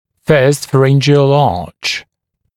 [fɜːst fəˈrɪn(d)ʒɪəl ɑːʧ][фё:ст фэˈрин(д)жиэл а:ч]первая фарингеальная дуга